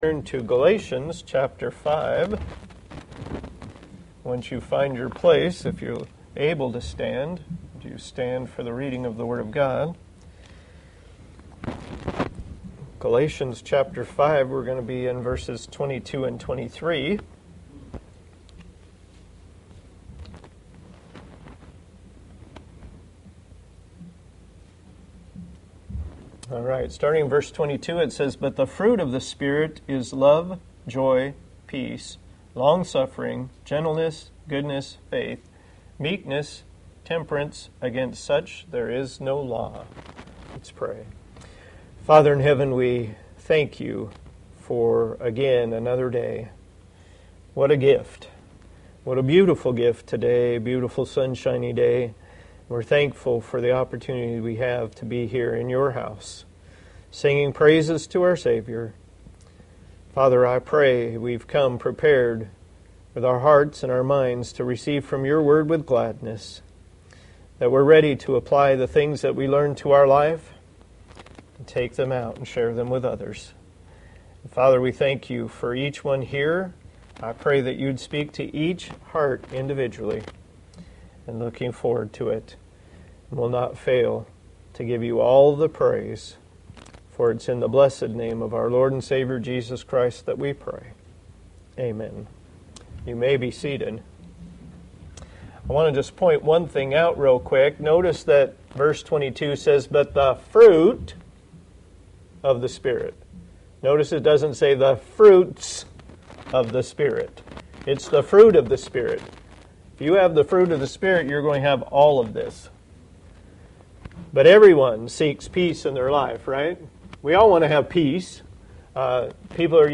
Passage: Galatians 5:22-23 Service Type: Sunday Morning